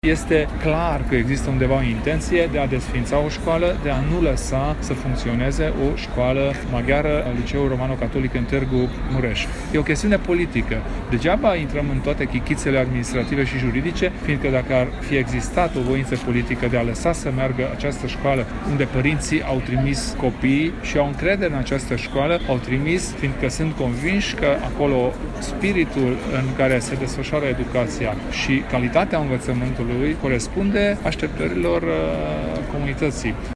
Peste 2.000 de persoane au protestat aseară la Tîrgu-Mureș.
Președintele UDMR, Kelemen Hunor, a declarat aseară că pentru comunitate este clar că există o intenție de a desființa Liceul Romano-Catolic: